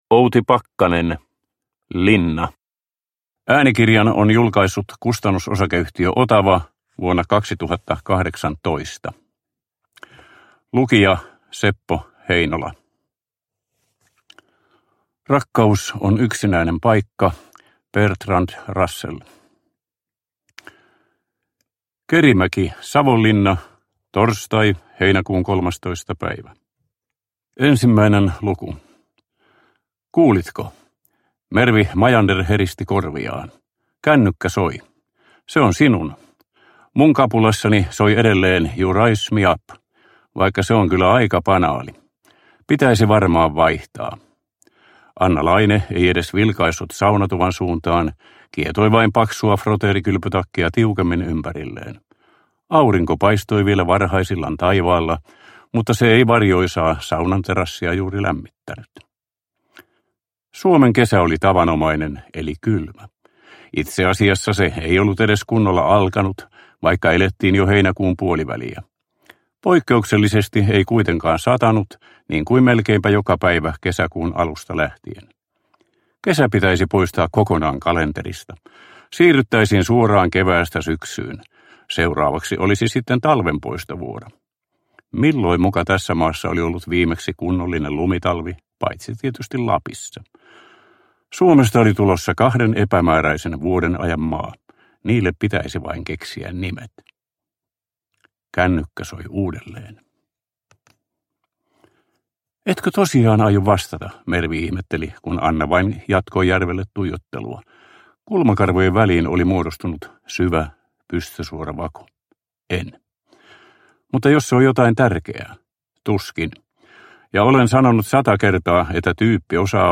Linna – Ljudbok – Laddas ner